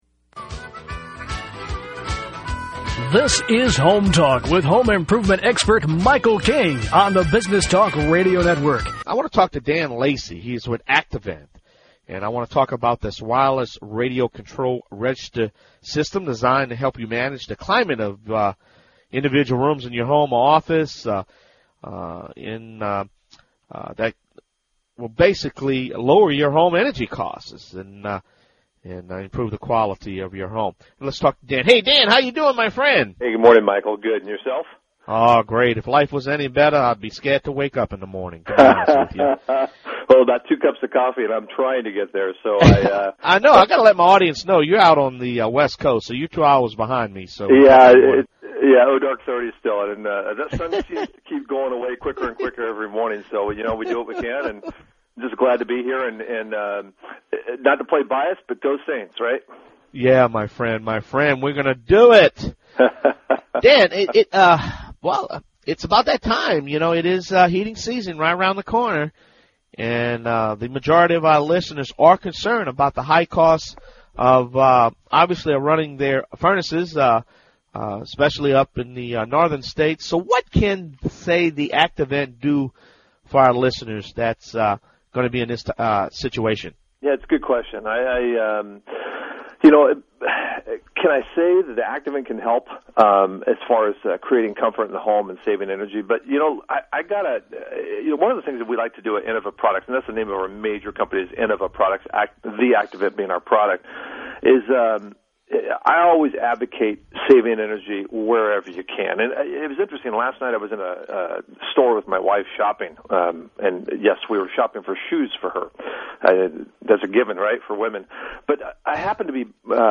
HomeTalk USA 10/3/2009 Interview
The Activent has been featured on Home Talk USA Radio as a featured energy saving product. Learn how The Activent creates wireless zoning by actively controlling the registers in your home.